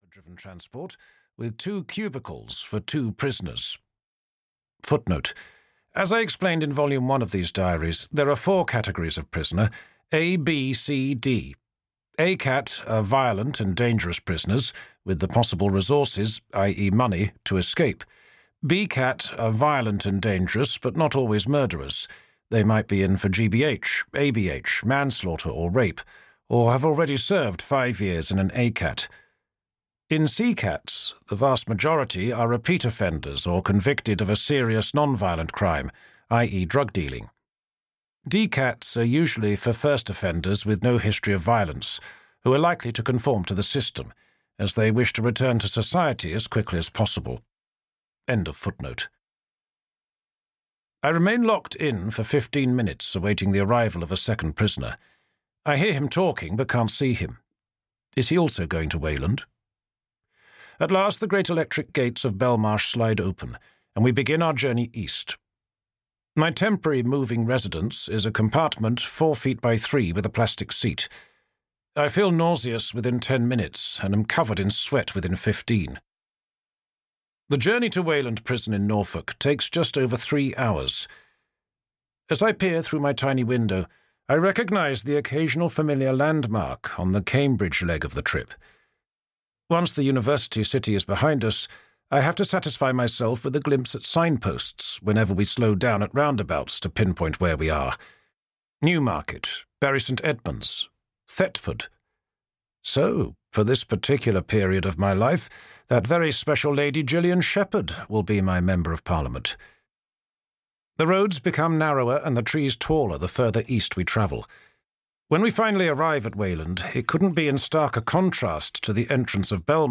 A Prison Diary II - Purgatory (EN) audiokniha
Ukázka z knihy